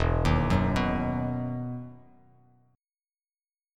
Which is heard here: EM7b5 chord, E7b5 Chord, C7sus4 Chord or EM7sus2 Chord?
EM7sus2 Chord